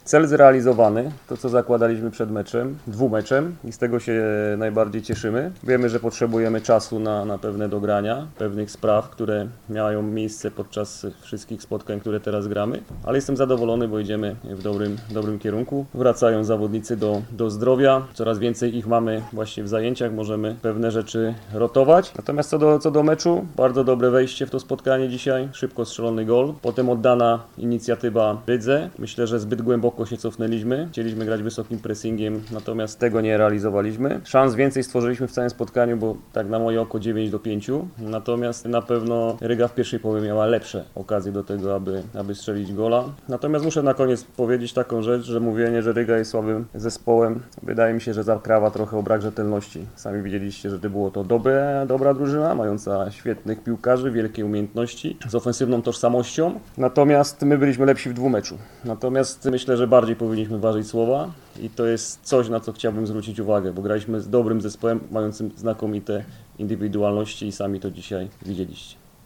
– Żeby mówić o drużynie „rodzina”, to trzeba zbudować relacje. Najważniejsze jest to, abyśmy pracowali i budowali tę drużynę – powiedział trener Jacek Magiera na konferencji pomeczowej. Szkoleniowiec wrocławian podsumował także występ swojej drużyny.